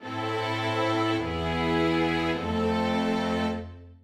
偽終止の例:VImIIIIV
c1-cadence-deceptive-min.mp3